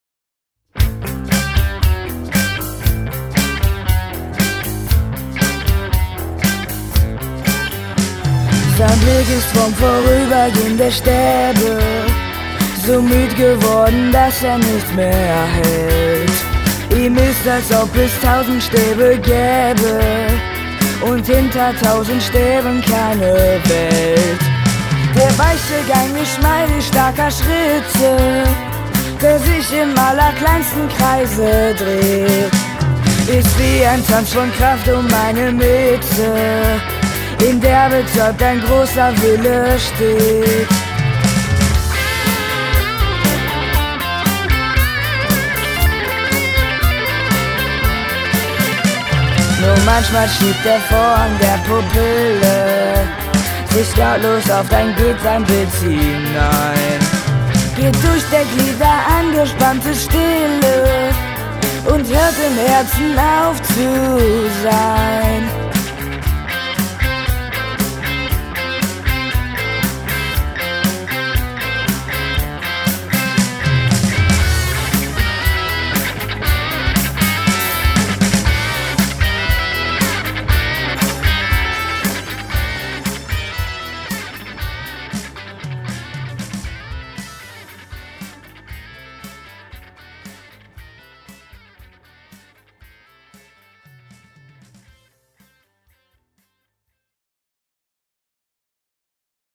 Leadvocals
Drums